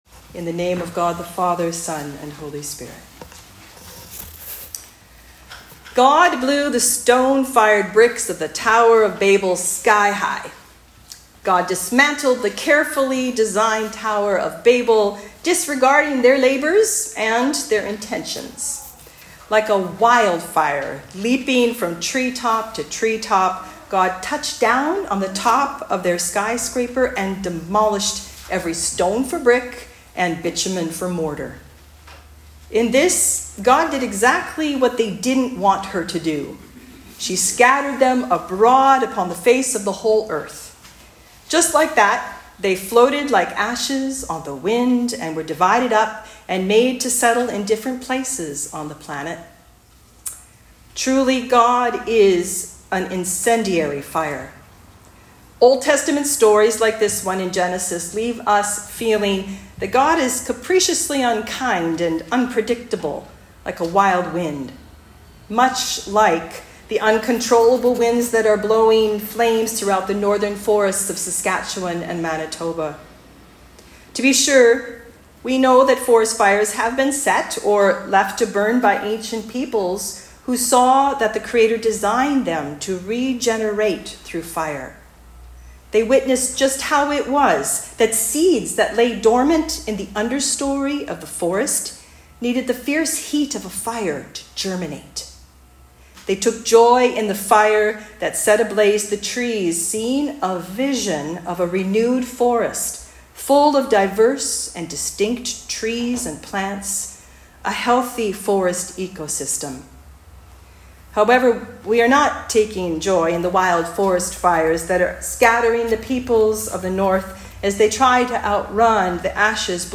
Pentecost Talk